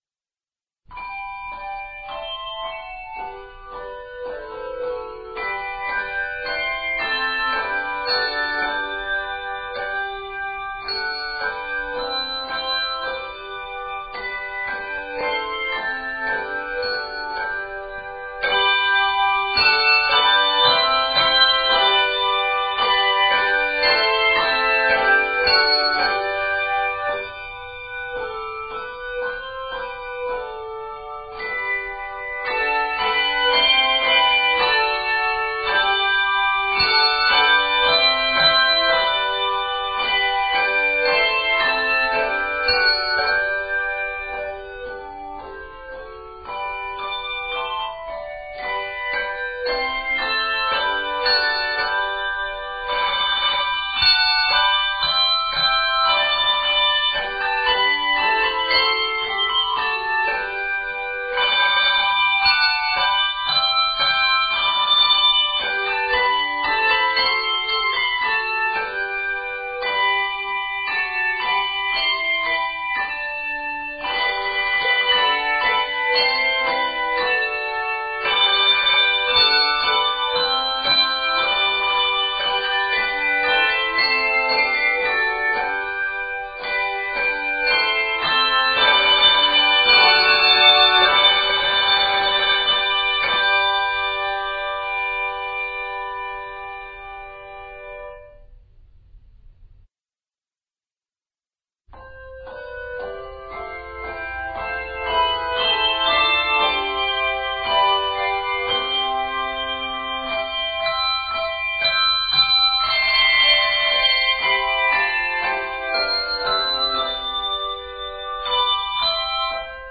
for 2-3 octave choirs
it contains easy chordal arrangements.
There are very few, if any, eighth notes or lower.